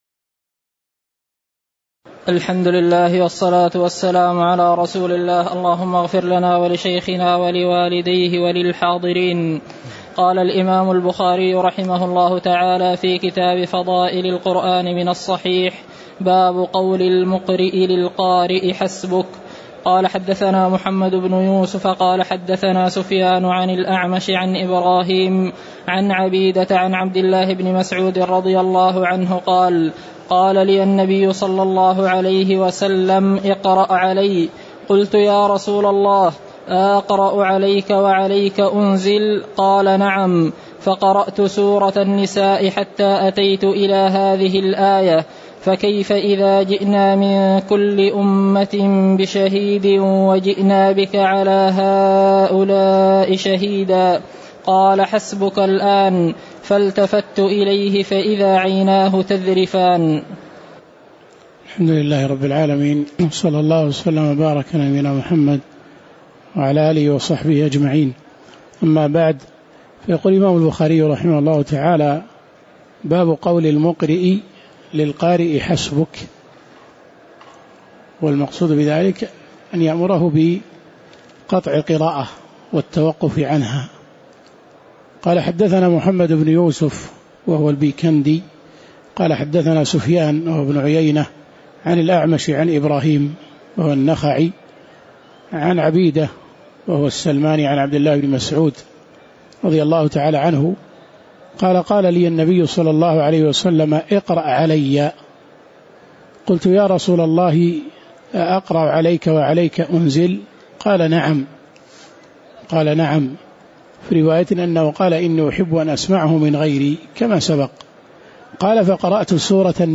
تاريخ النشر ٢١ رمضان ١٤٣٩ هـ المكان: المسجد النبوي الشيخ